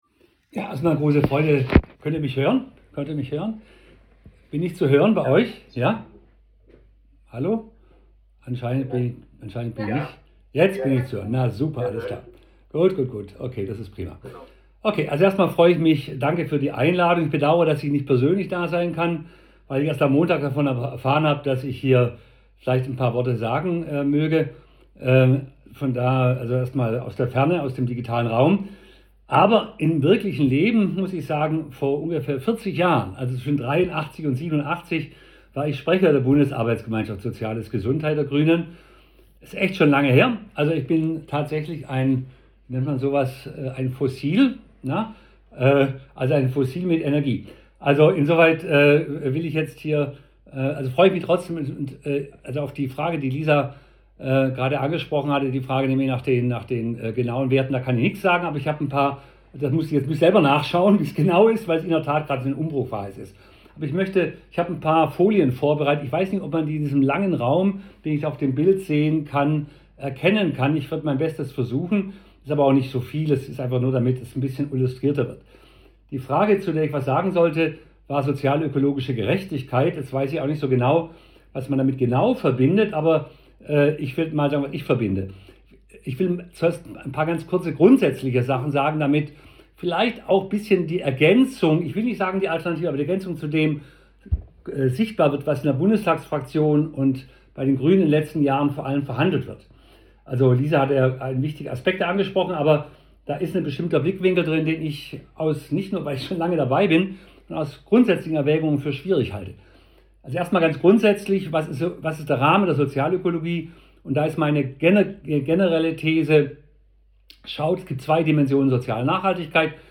Vortrag
Und hier die Audioaufnahme von Input und zwei anschließenden Antworten: